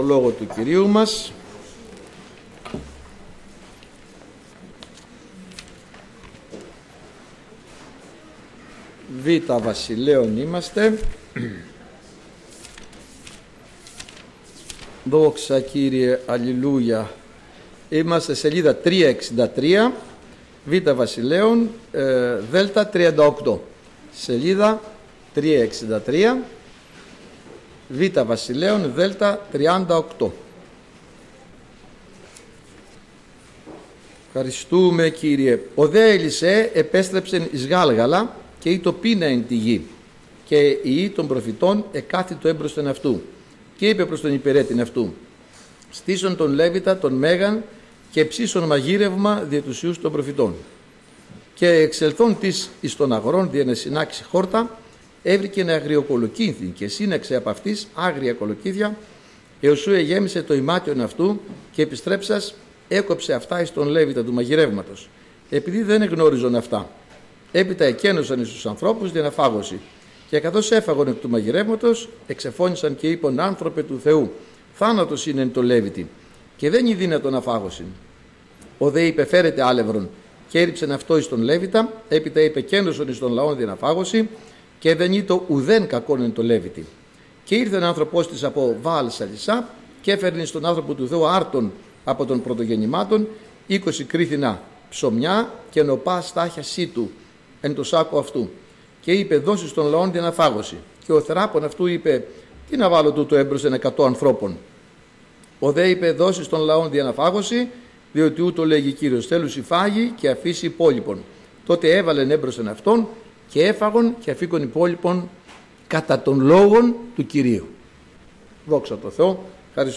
Κυριακάτικα Ημερομηνία